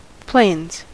Ääntäminen
Ääntäminen US Tuntematon aksentti: IPA : /pleɪnz/ Haettu sana löytyi näillä lähdekielillä: englanti Käännöksiä ei löytynyt valitulle kohdekielelle.